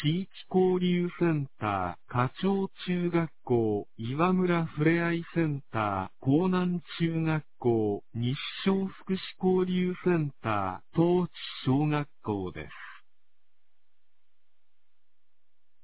放送音声
2024年08月08日 21時36分に、南国市より放送がありました。